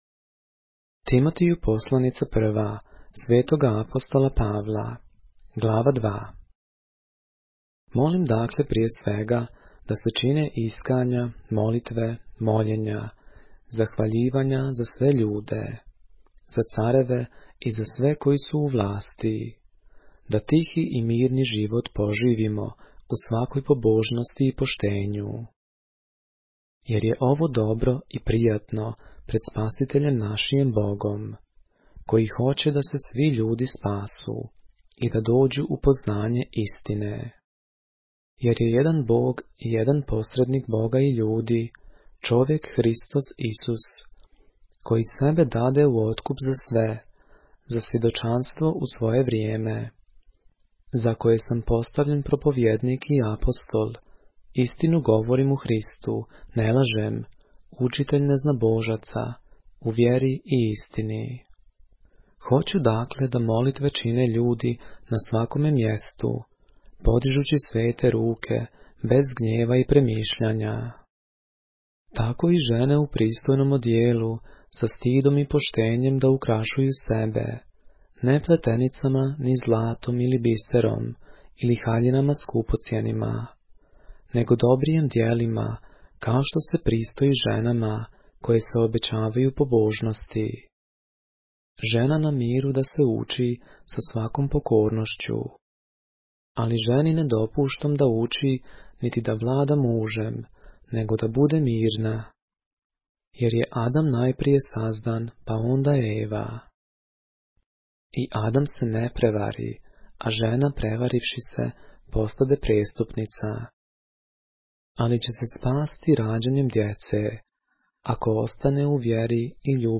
поглавље српске Библије - са аудио нарације - 1 Timothy, chapter 2 of the Holy Bible in the Serbian language